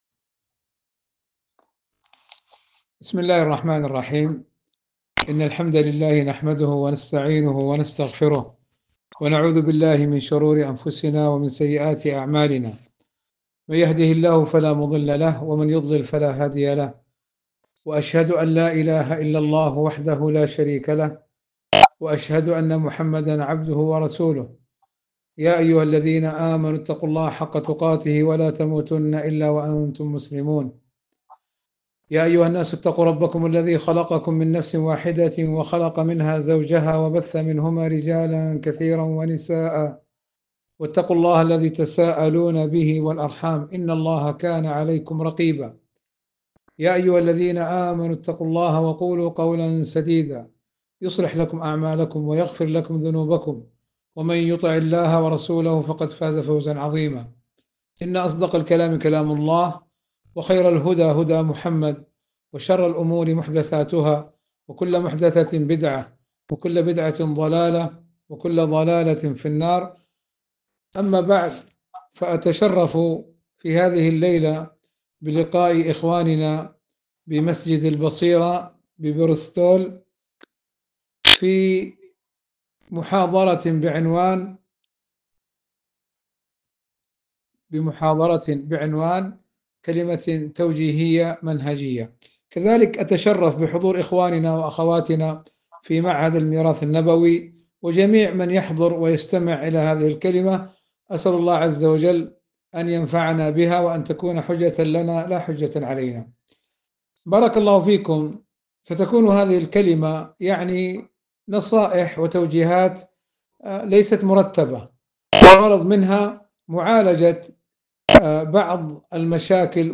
محاضرة بعنوان